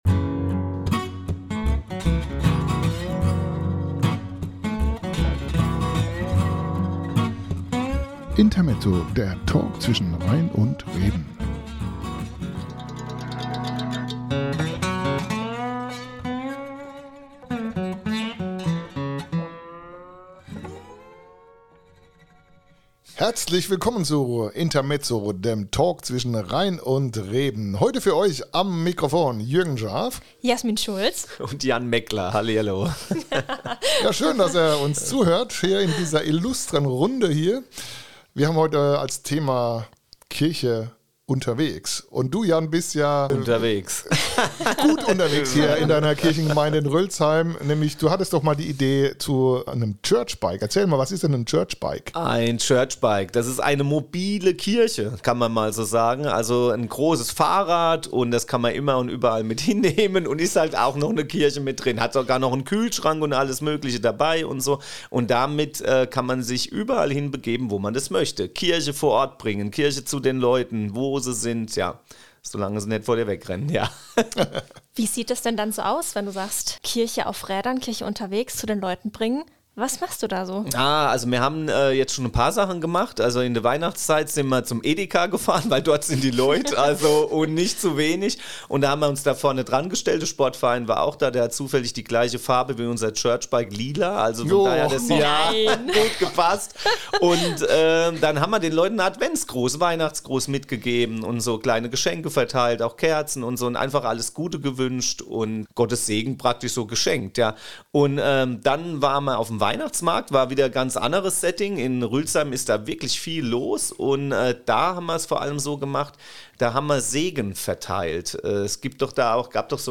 Beschreibung vor 1 Jahr Intermezzo - der Talk zwischen Rhein und Reben.